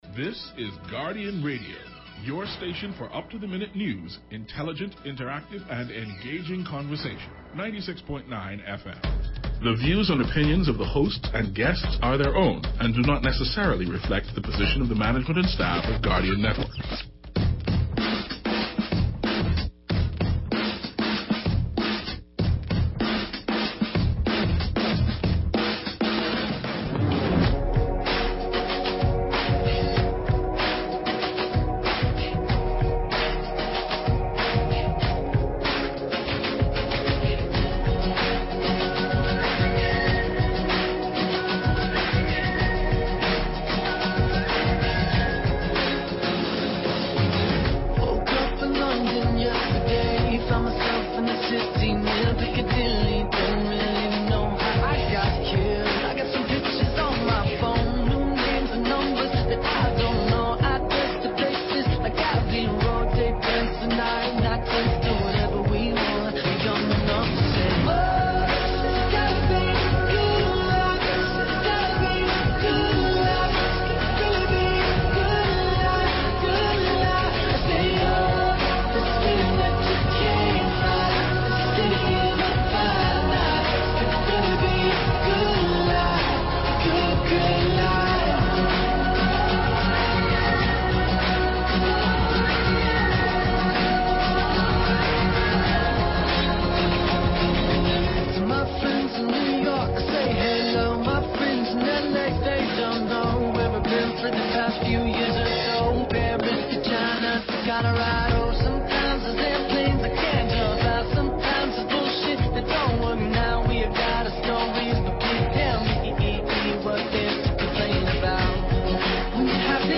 We will be speaking with representatives of The Division of Youth 242 of The Ministry of Youth, Sports and Culture Bahamas during our first segment, highlighting the activities happening during National Youth Month. We will also be speaking with the amazing actors and directors from Shakespeare in Paradise, who just wrapped up their annual festival, which featured matinees for school students!